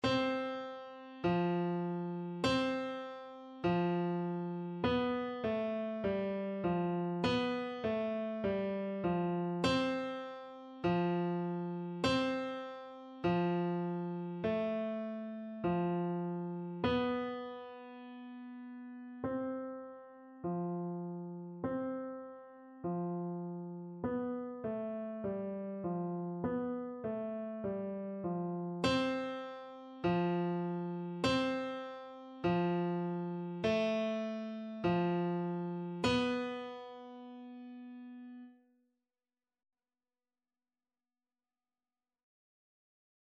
4/4 (View more 4/4 Music)
Beginners Level: Recommended for Beginners
Piano  (View more Beginners Piano Music)
Classical (View more Classical Piano Music)